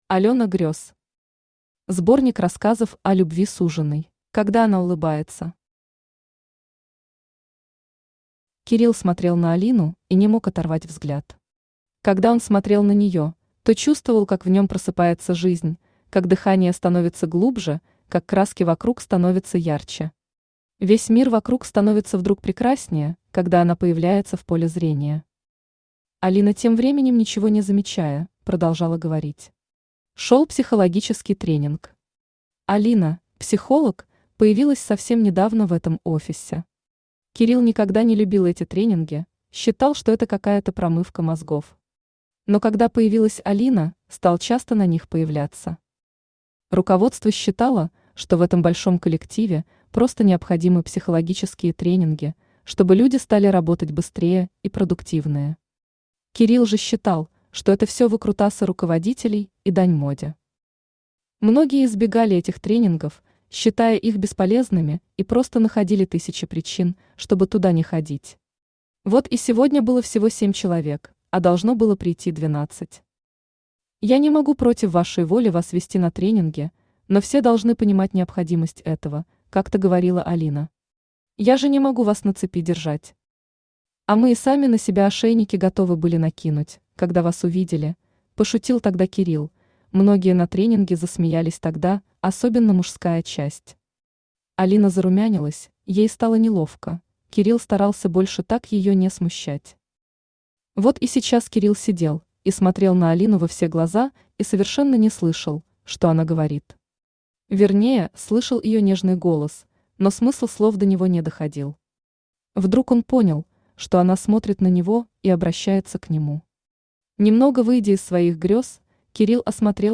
Аудиокнига Сборник рассказов о любви «Суженый» | Библиотека аудиокниг
Aудиокнига Сборник рассказов о любви «Суженый» Автор Алёна Грёз Читает аудиокнигу Авточтец ЛитРес.